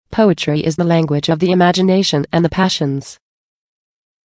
You will hear a sentence.